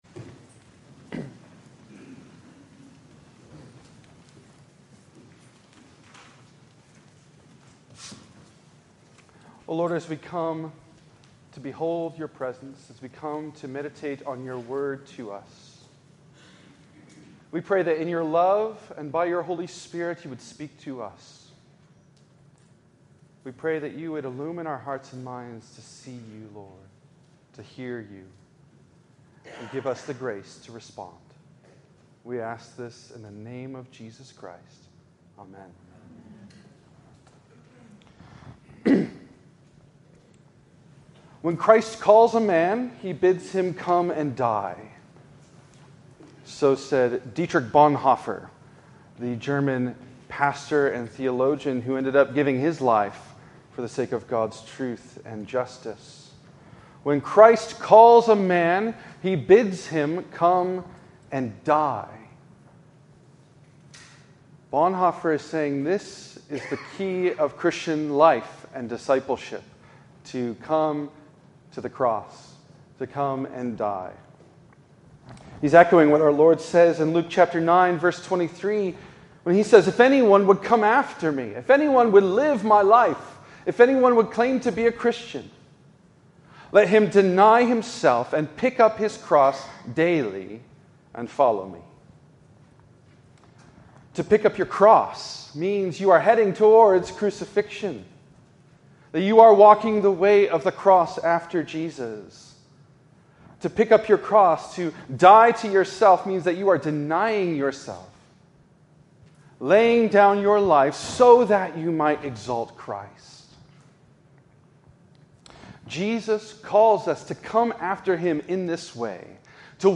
In this sermon on the second Sunday of Lent